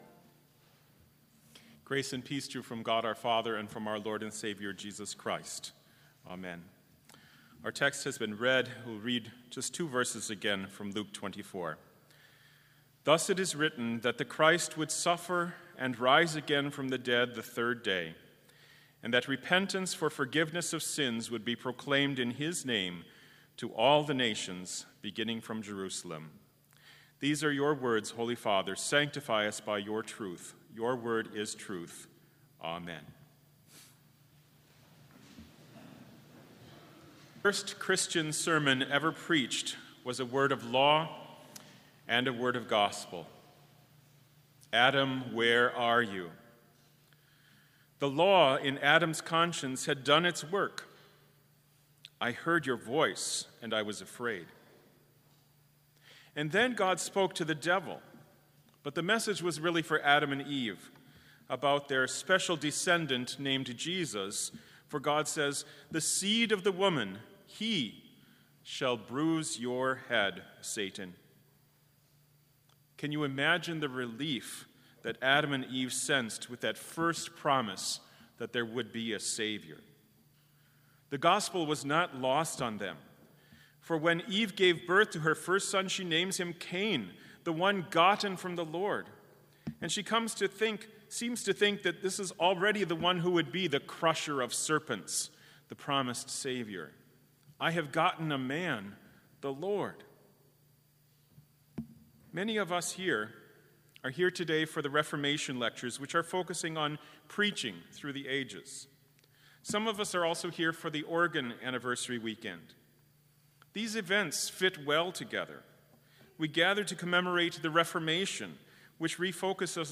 Complete service audio for Special - October 31, 2019